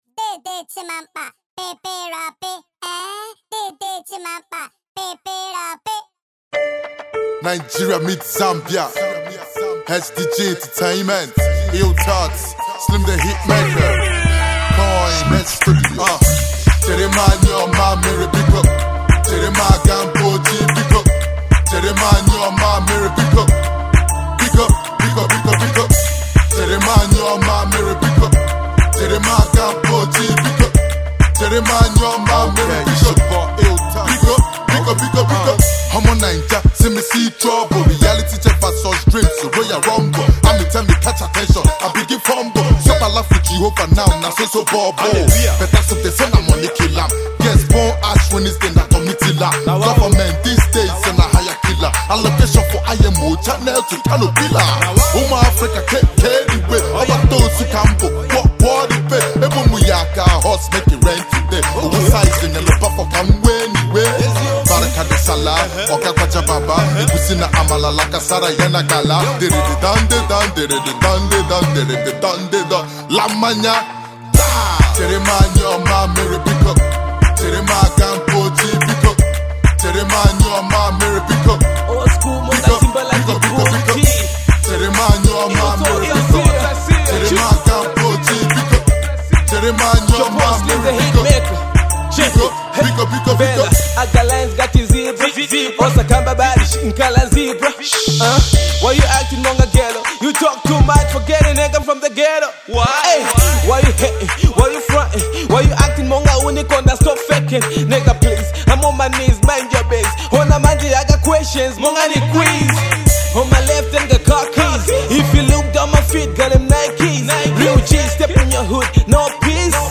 freestyle cover